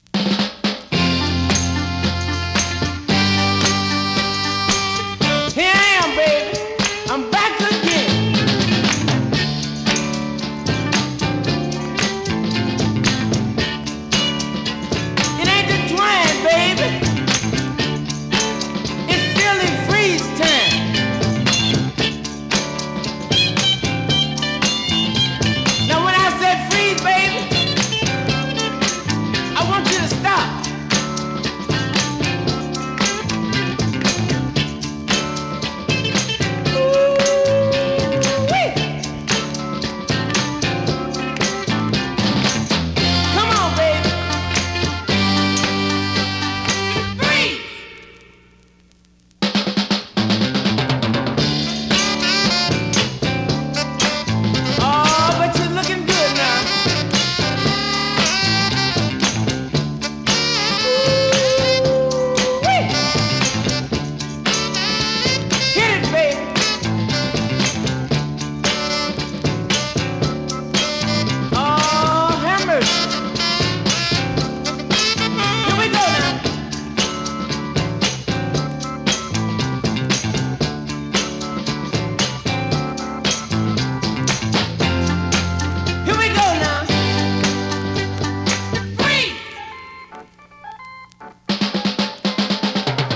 US 45 ORIGINAL 7inch シングル MOD FUNKY SOUL 試聴
試聴 (実際の出品物からの録音です)